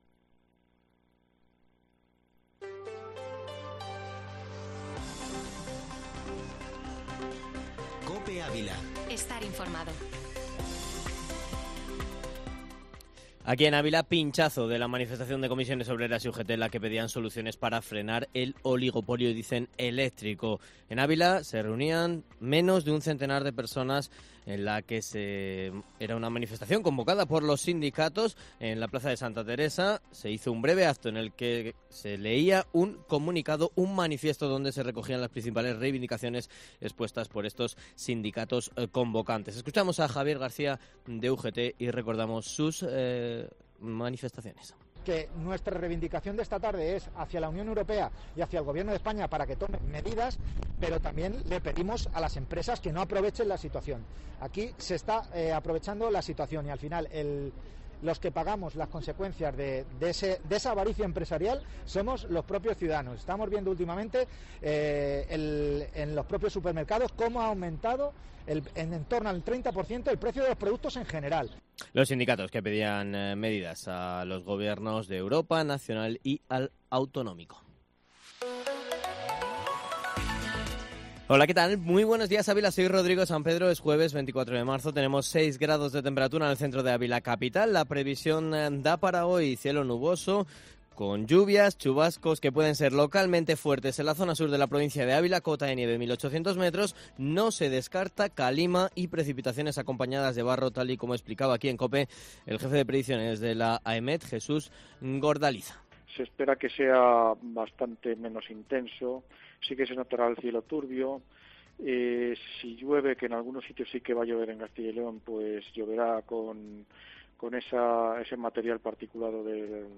Informativo Matinal Herrera en COPE Ávila -24-marzo